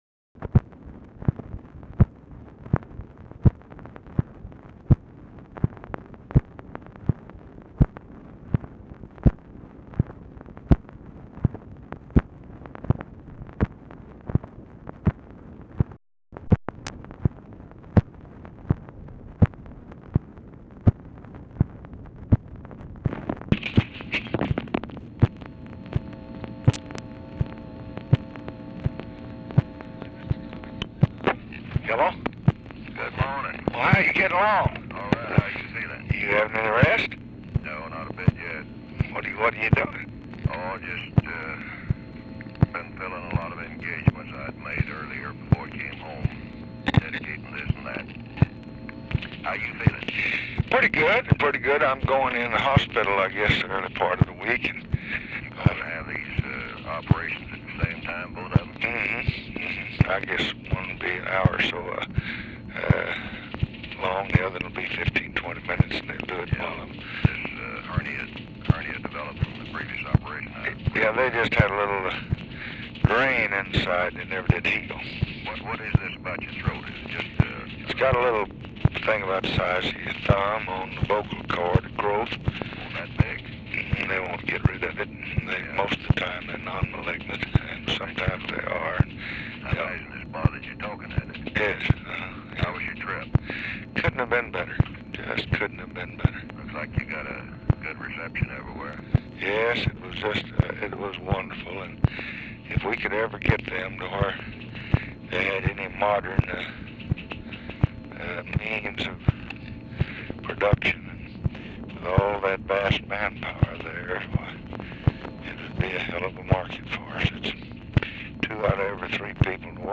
Telephone conversation
MILLS ON HOLD ABOUT 0:30
Format Dictation belt
Location Of Speaker 1 LBJ Ranch, near Stonewall, Texas